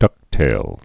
(dŭktāl)